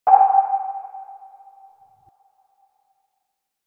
Radar Ping Sound Effect
Description: Radar ping sound effect. Sonar or radar electronic ping signal sound effect. Scan detection beep tone.
Radar-ping-sound-effect.mp3